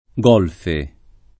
golf [ g0 lf ] s. m.